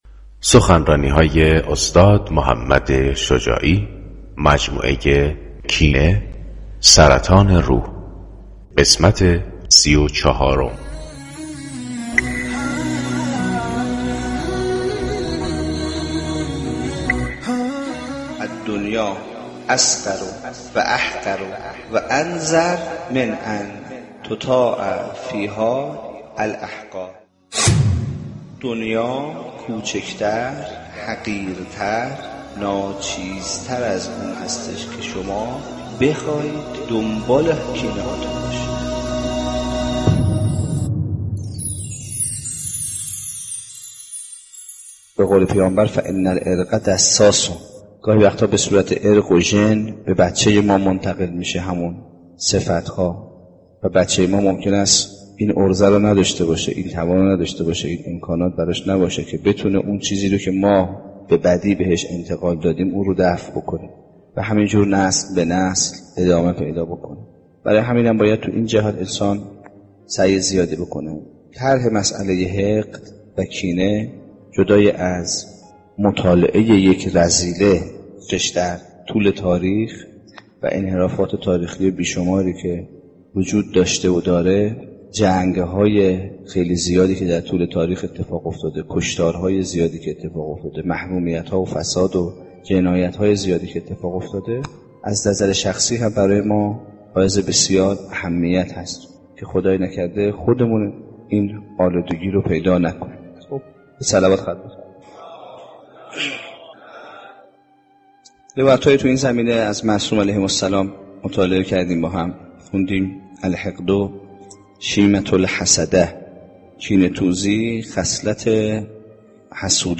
سخنرانی جدید